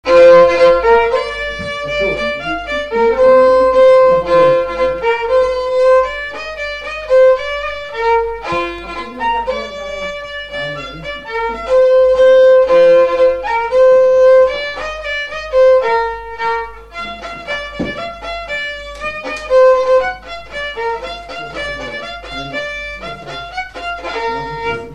gestuel : à marcher
circonstance : fiançaille, noce
Pièce musicale inédite